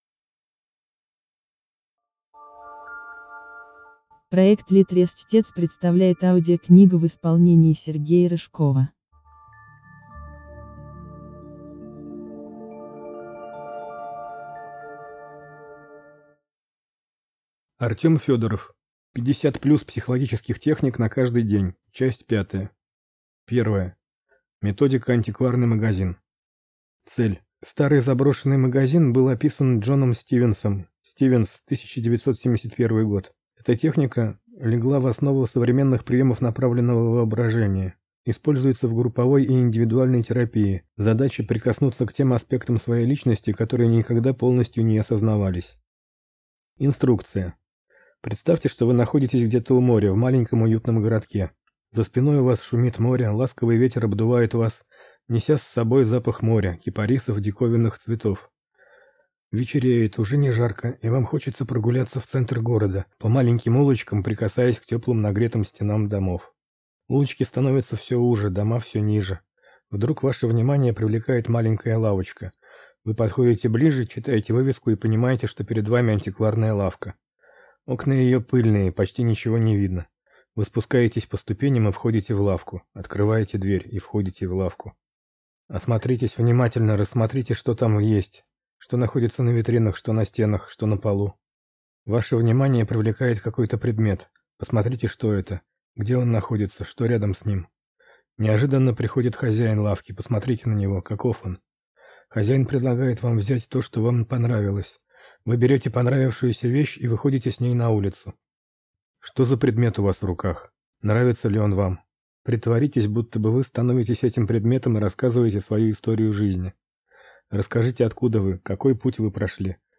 Аудиокнига 50+ психологических техник на каждый день. Часть 5 | Библиотека аудиокниг